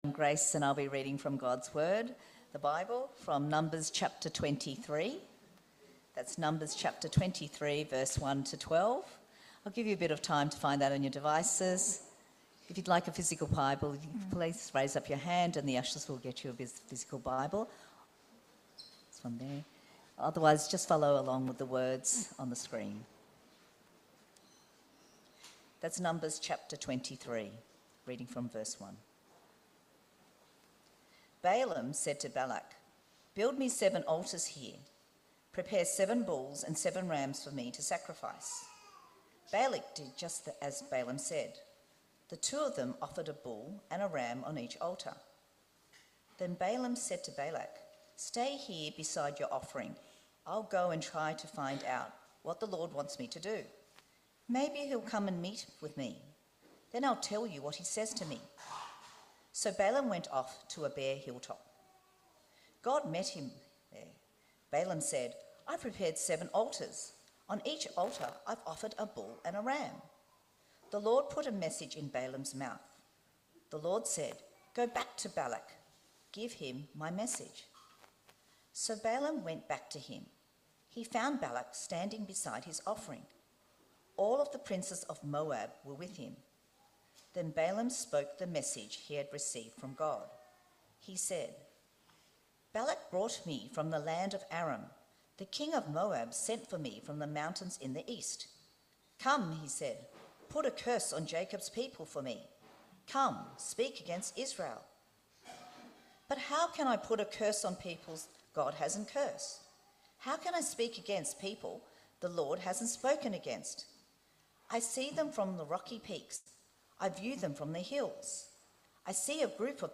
Numbers 22-26 Service Type: 10:45 English